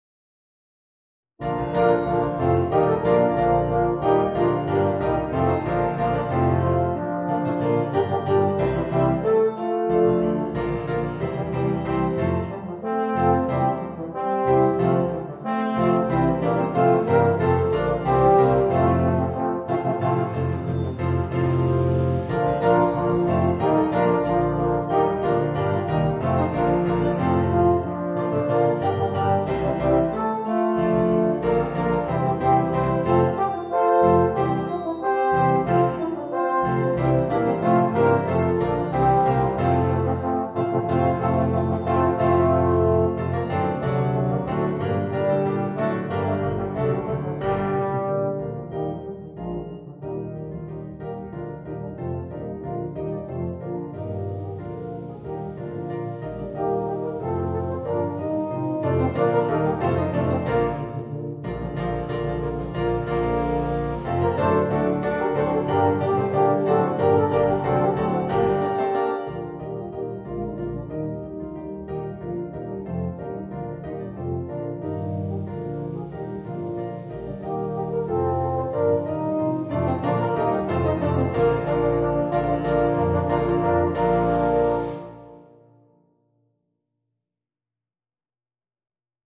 Voicing: 2 Baritones, 2 Euphoniums, 4 Tubas